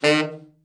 TENOR SN   8.wav